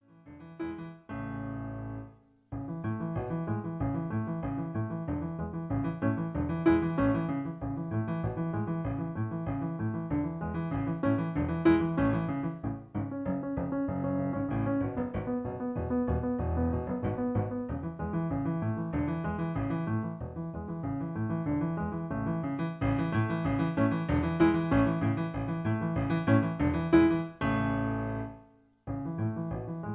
In A. Piano Accompaniment